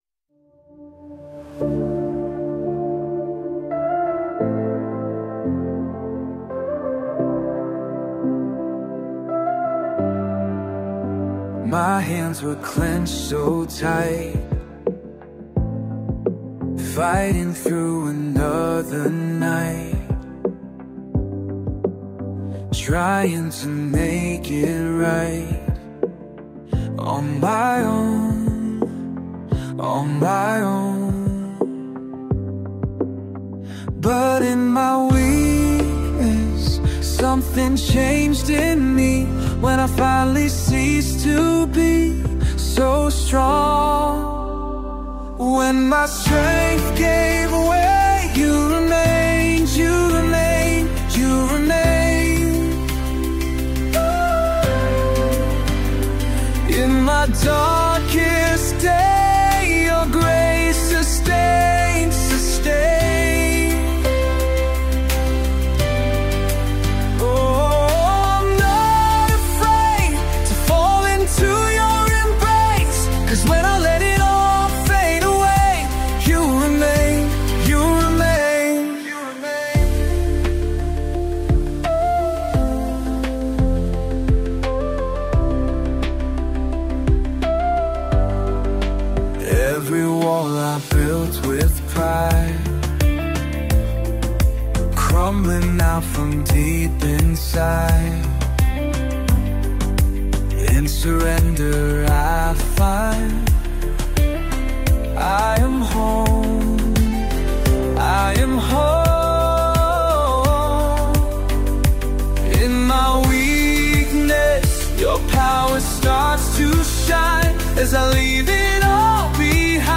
Worship Praise - Jesus Remain
You-RemainedCCM.mp3